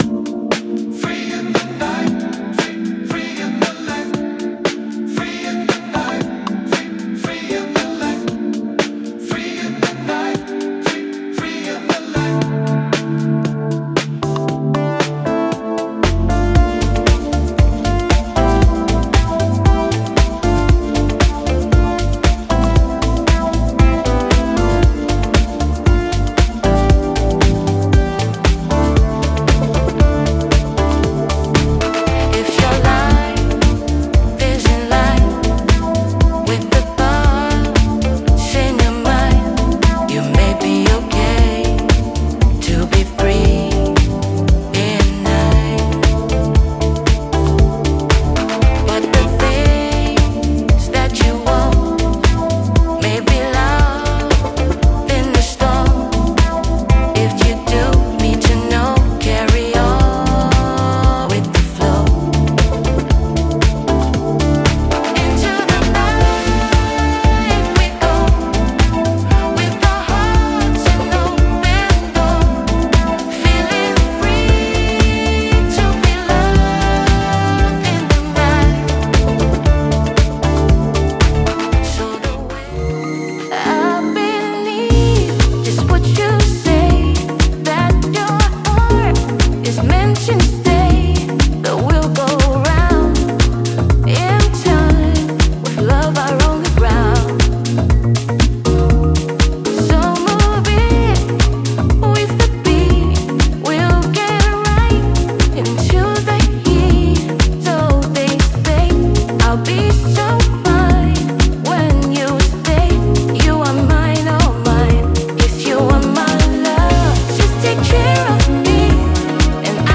デモサウンドはコチラ↓
Genre:Nu Disco
59 Vocal Parts
9 Guitar Parts
16 Synth Parts